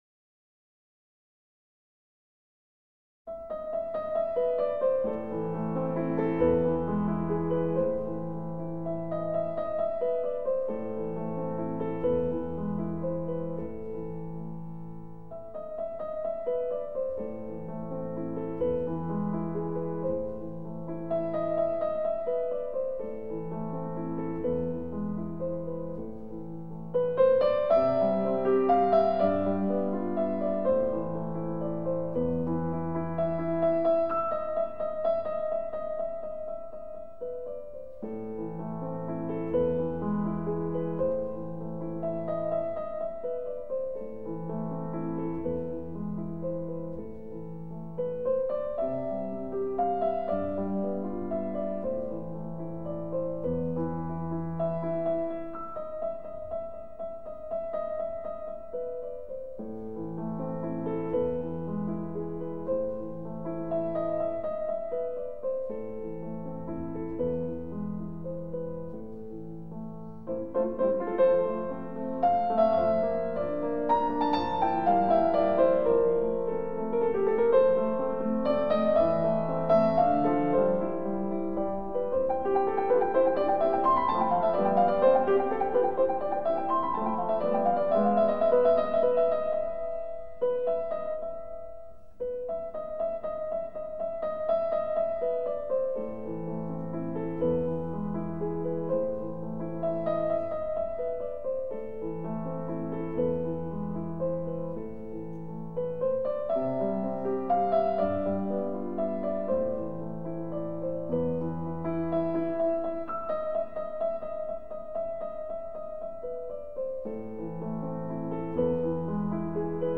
موسیقی بی‌کلام "برای الیزه"، آهنگساز: بتهوون